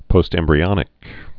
(pōstĕm-brē-ŏnĭk)